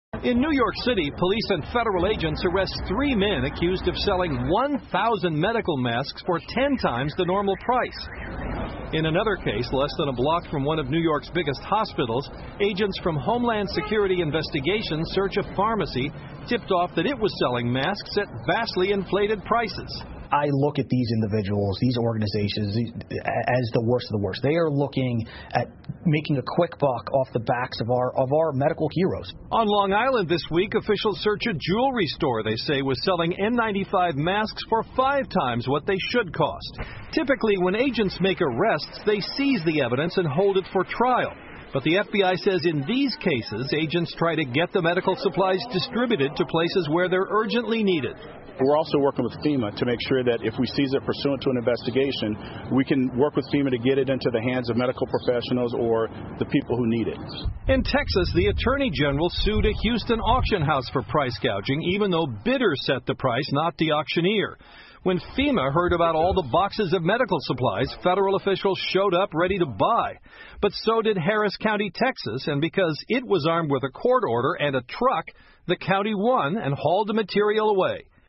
NBC晚间新闻 美警方打击口罩恶意涨价 听力文件下载—在线英语听力室